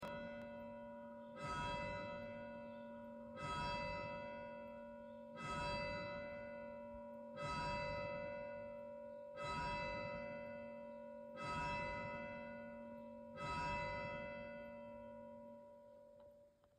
Sonnerie cloche du 25/07/2024 10h00